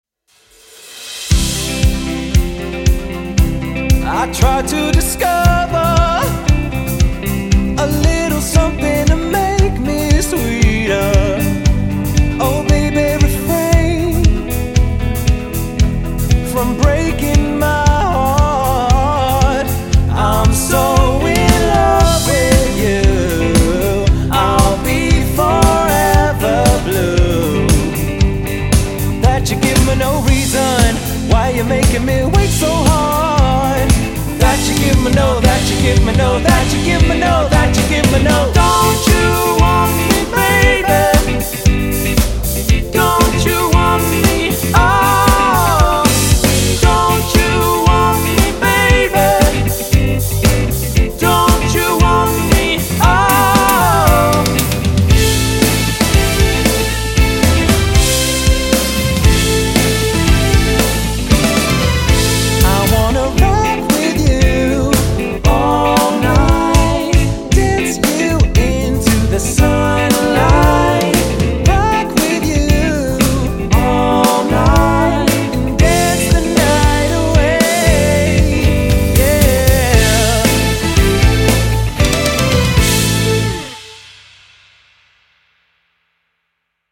Vocals, Vocals/Guitar, Bass, Drums